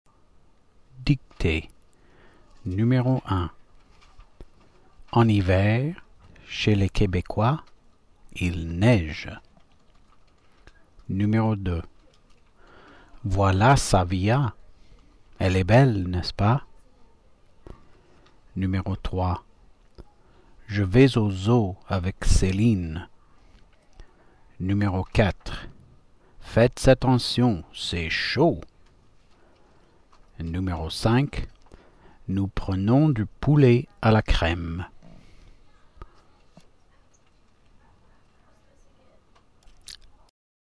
la Dictée*